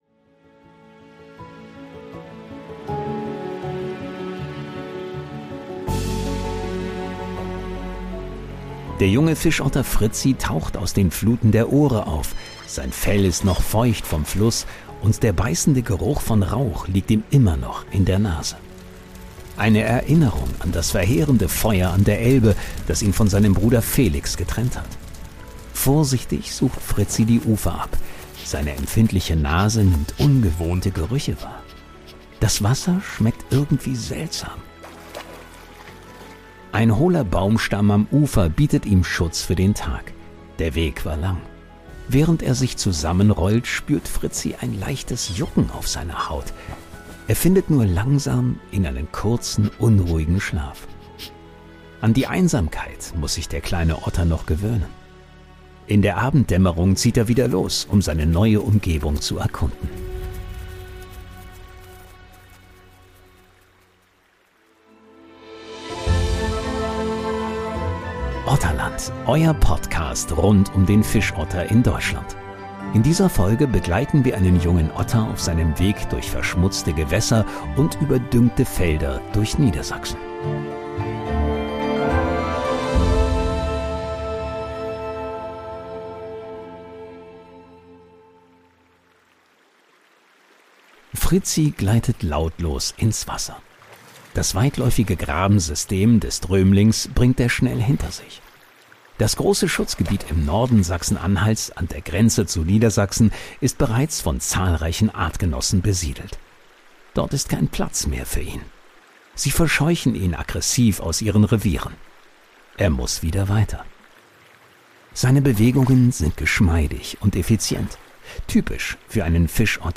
„Otterland“ ist ein Storytelling-Podcast, der eine fiktive Geschichte über eine Otterfamilie quer durch Deutschland erzählt, basierend auf realen Erfahrungen und Fakten zu Fischottern. Ein Hörabenteuer über Mut, Hoffnung, Verlust und die Suche nach einem Zuhause.